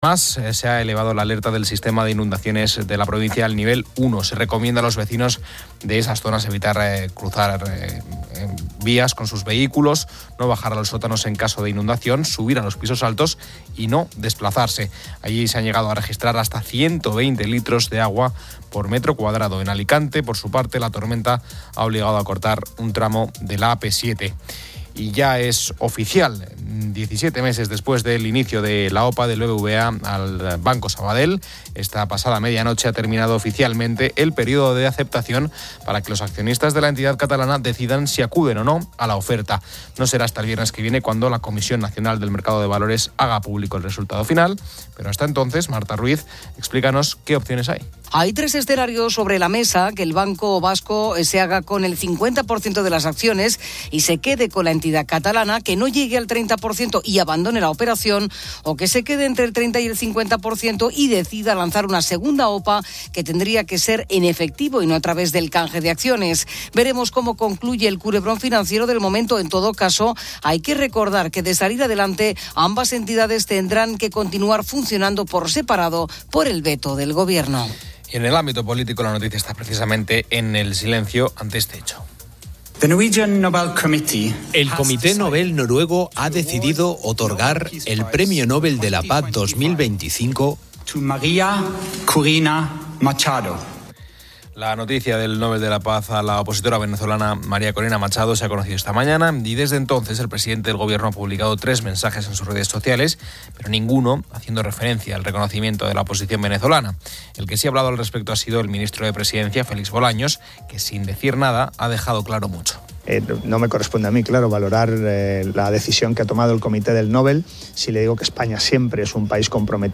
Un experto veterinario describe las picaduras de escorpión y el pez araña, destacando que estas últimas requieren calor para el tratamiento.